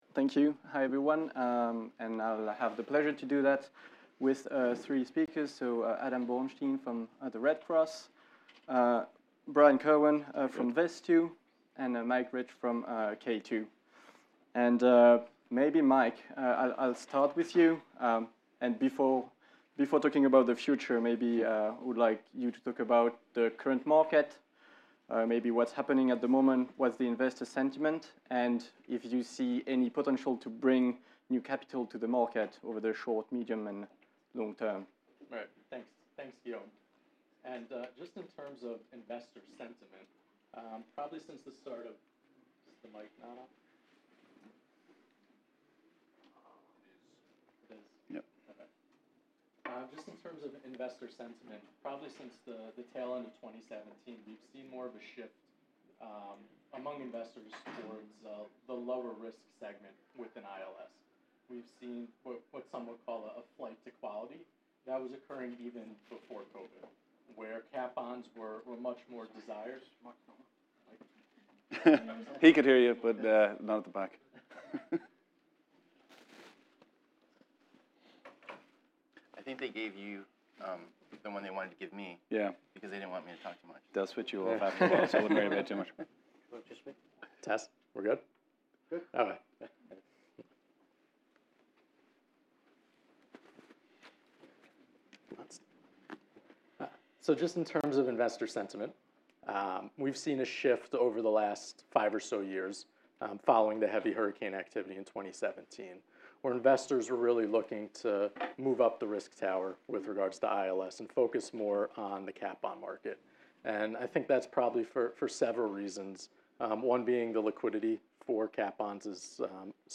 This was the seventh session of the day from our Artemis London 2022 conference, held on September 6th, 2022.